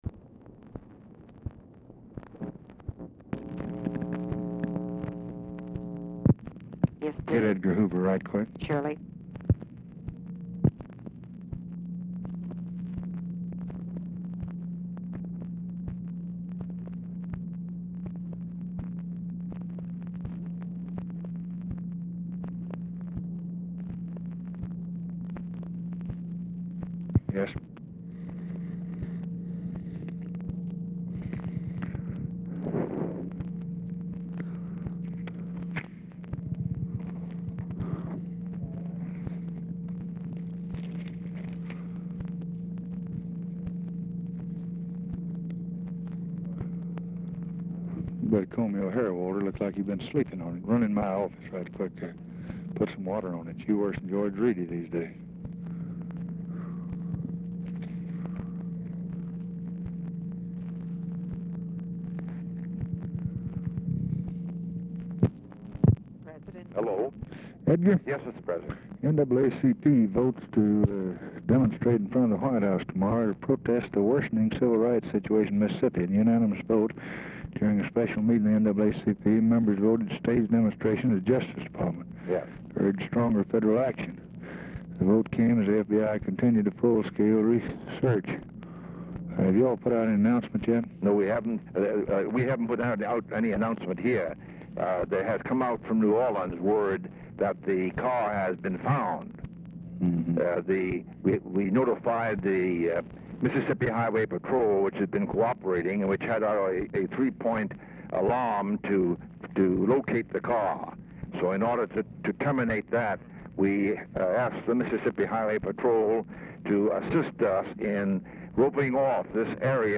Telephone conversation # 3853, sound recording, LBJ and J. EDGAR HOOVER, 6/23/1964, 5:35PM | Discover LBJ
Format Dictation belt
Location Of Speaker 1 Oval Office or unknown location
Specific Item Type Telephone conversation